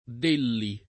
delli [ d % lli ]